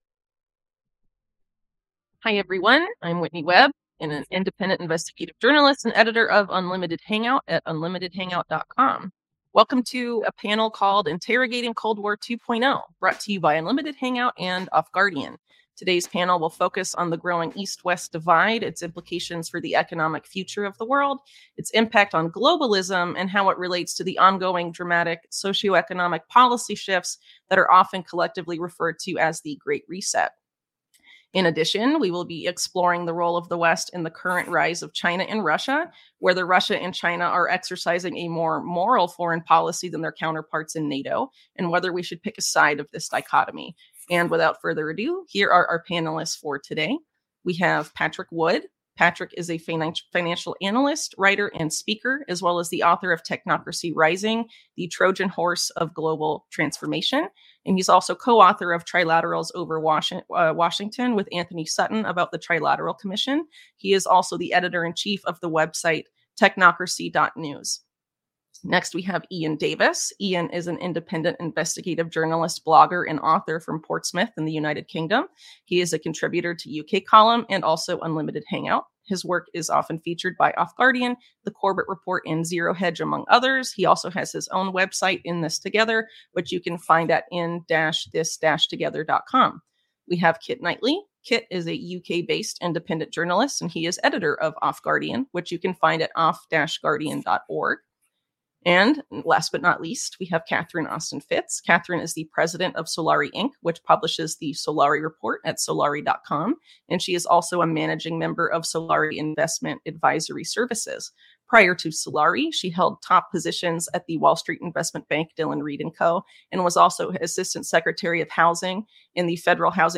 In this video panel discussion brought to you by Unlimited Hangout and OffGuardian, several panelists discuss the nature of the East-West dichotomy and whether the rise of Eurasia and the fall of the West were engineered by certain factions of global elites and for what purpose.